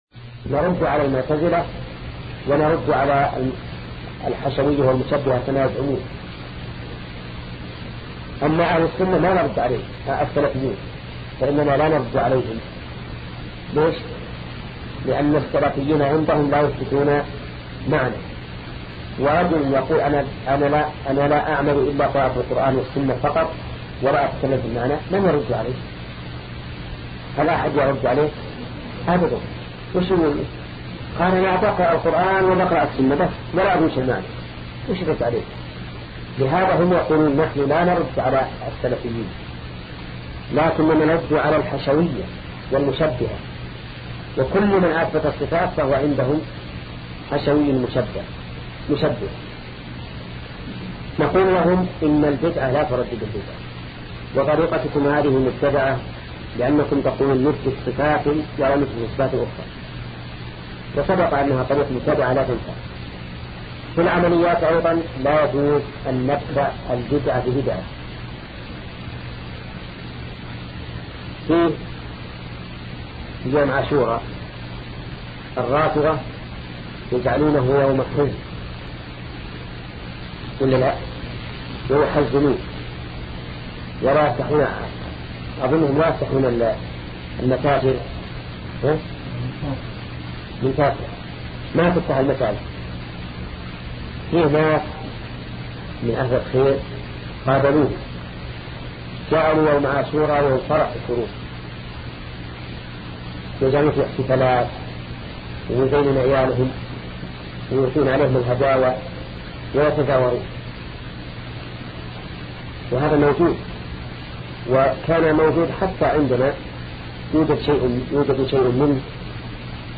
سلسلة مجموعة محاضرات شرح القواعد المثلى لشيخ محمد بن صالح العثيمين رحمة الله تعالى